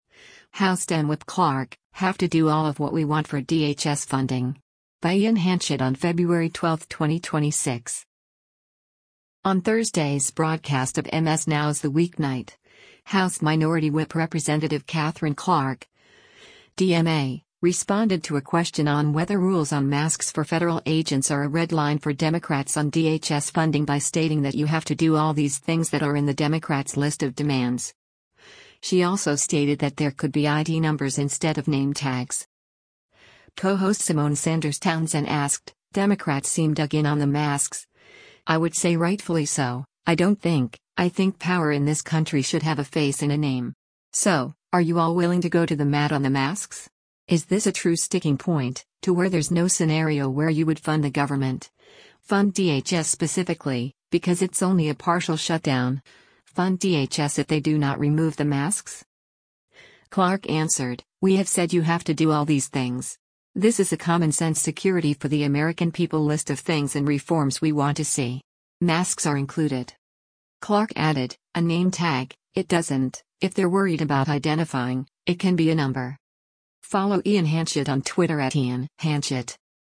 On Thursday’s broadcast of MS NOW’s “The Weeknight,” House Minority Whip Rep. Katherine Clark (D-MA) responded to a question on whether rules on masks for federal agents are a red line for Democrats on DHS funding by stating that “you have to do all these things” that are in the Democrats’ list of demands. She also stated that there could be ID numbers instead of name tags.